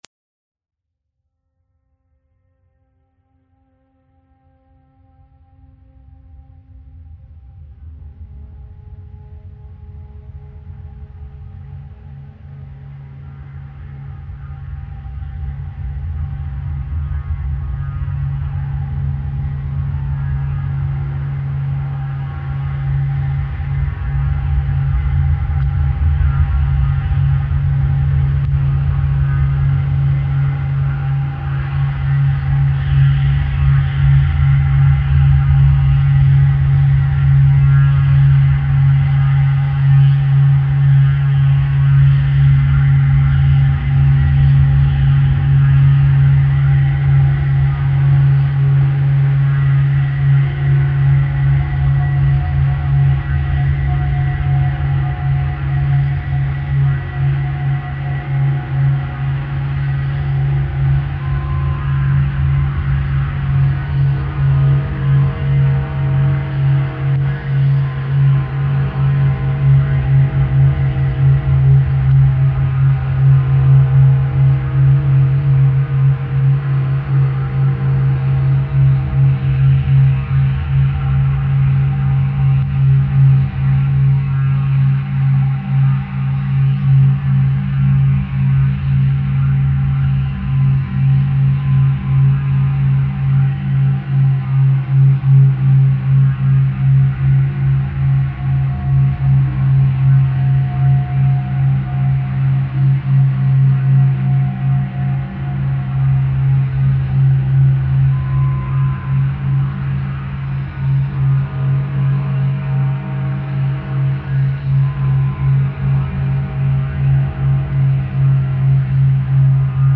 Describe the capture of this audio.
All the tracks were re-mastered on purpose.